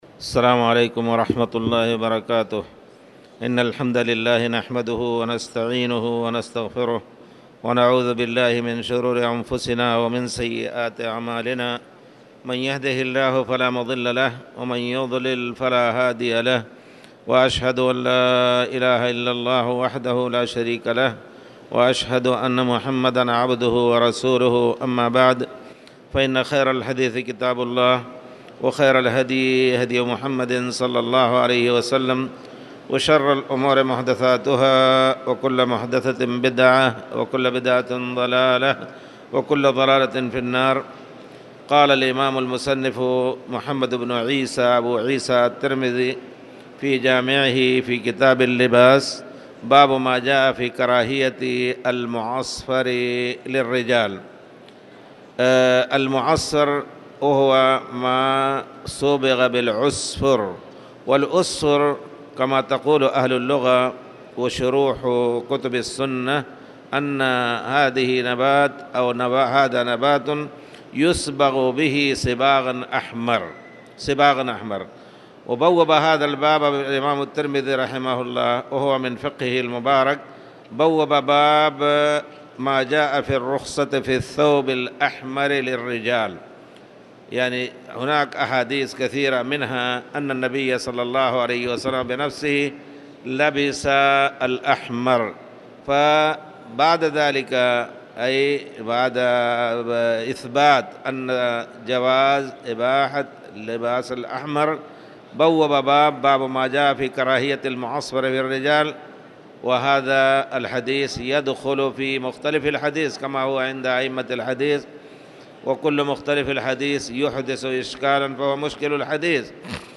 تاريخ النشر ٢٤ صفر ١٤٣٨ هـ المكان: المسجد الحرام الشيخ